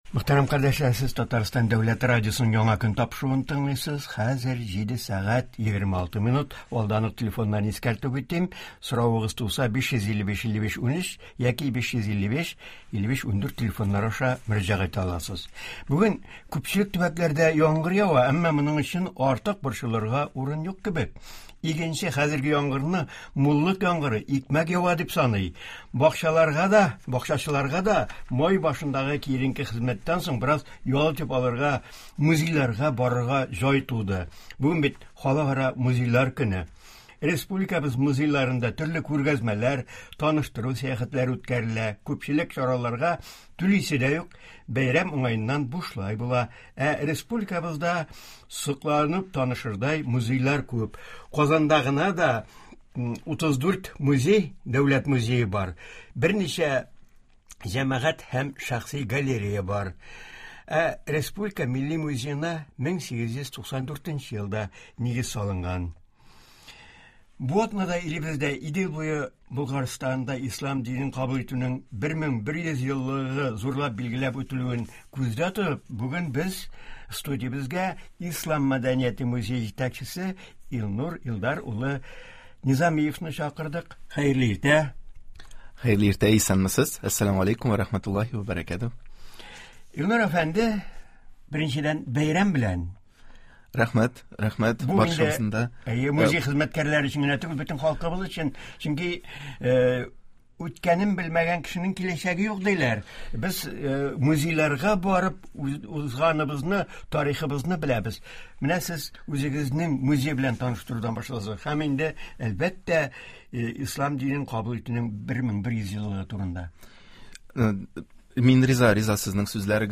Туры эфир (18.05.2022)